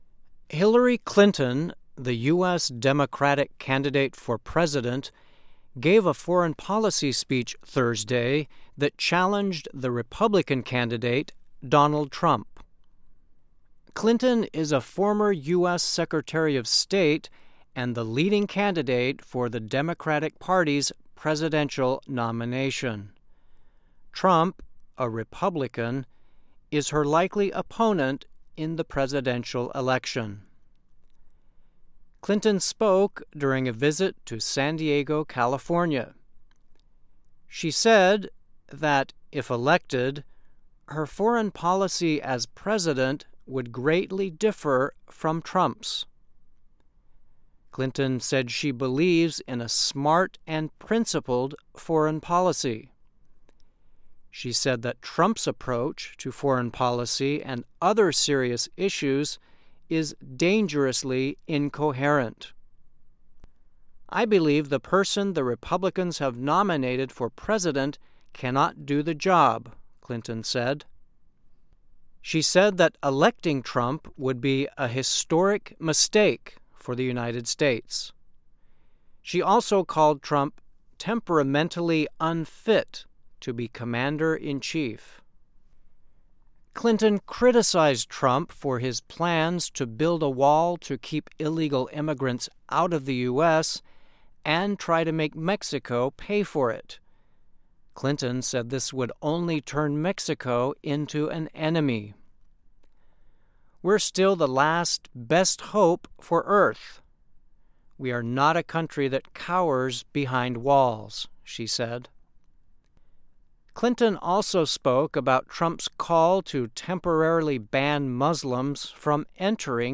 by VOA - Voice of America English News